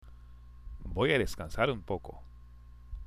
（ボイア　デスカンサール　ウンポコ）